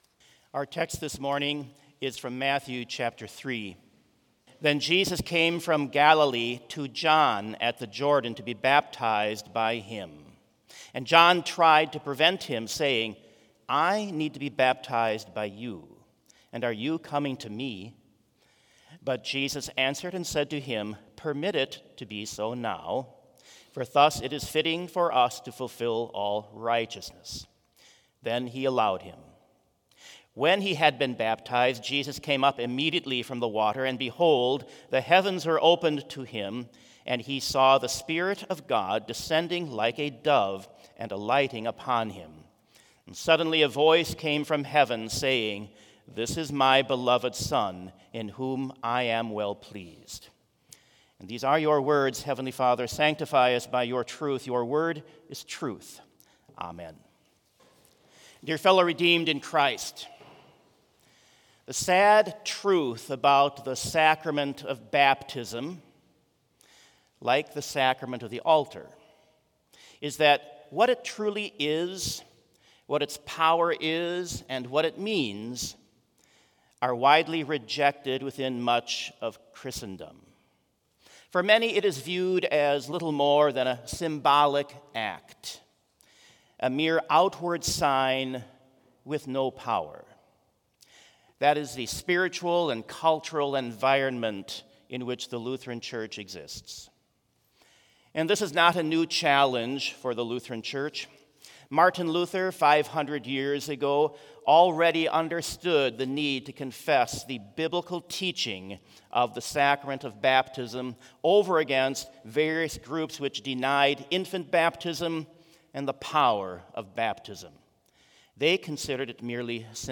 Complete Service
• Prelude
• Hymn 246 - God's Own Child, I Gladly Say It View
• Reading: Matthew 3:13-17 View
• Devotion
This Chapel Service was held in Trinity Chapel at Bethany Lutheran College on Monday, February 12, 2024, at 10 a.m. Page and hymn numbers are from the Evangelical Lutheran Hymnary.